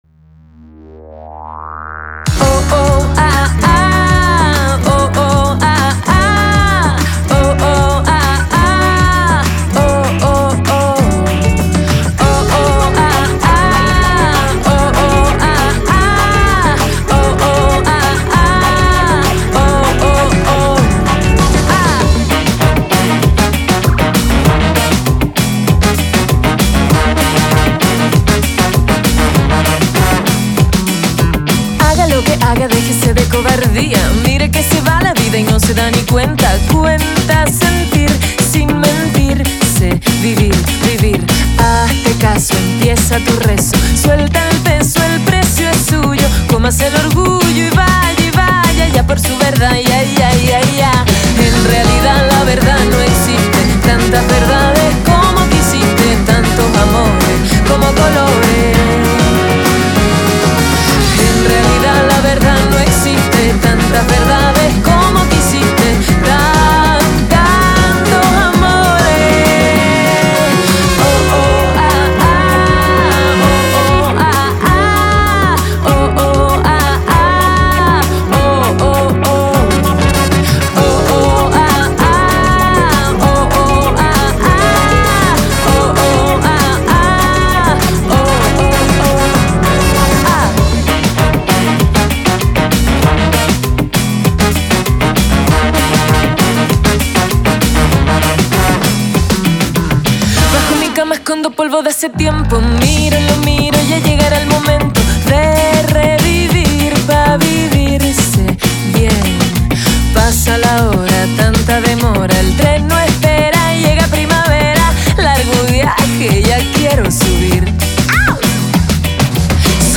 vocalista y compositora
guitarrista y arreglista
bajista